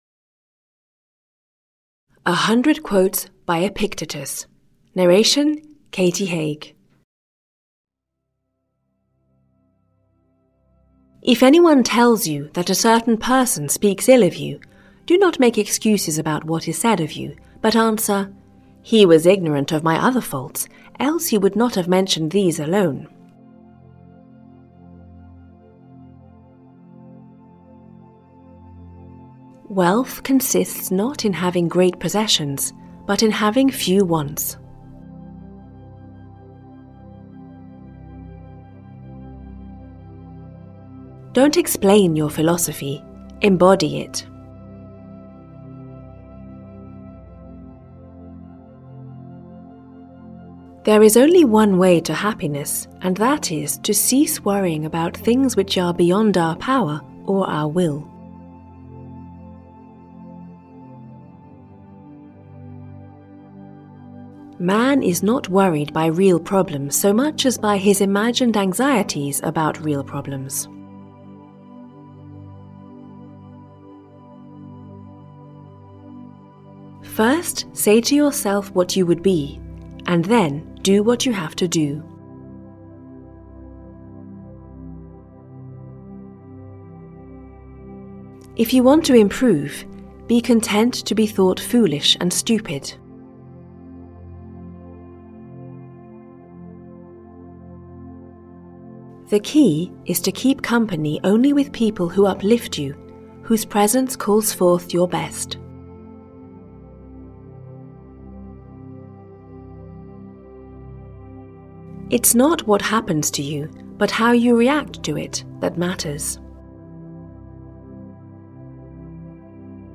Audio kniha100 Quotes by Epictetus: Great Philosophers & Their Inspiring Thoughts (EN)
Ukázka z knihy